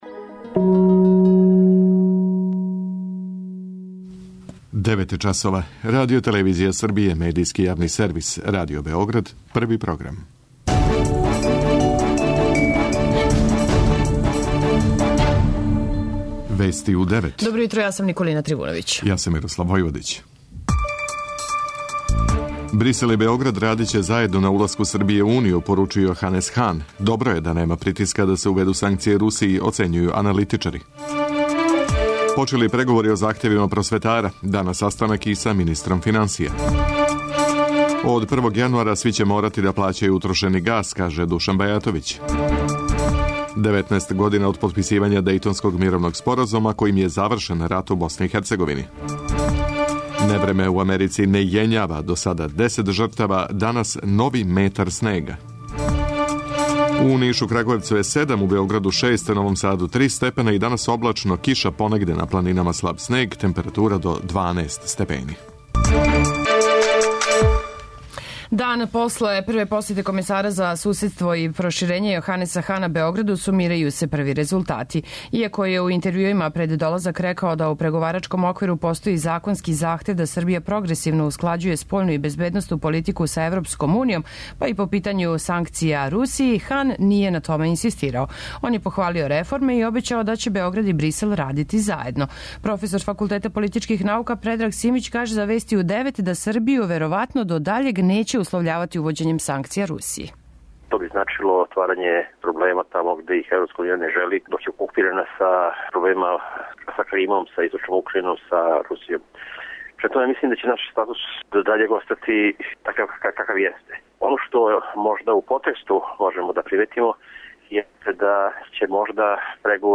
Највиша дневна температура до 12 степени. преузми : 10.36 MB Вести у 9 Autor: разни аутори Преглед најважнијиx информација из земље из света.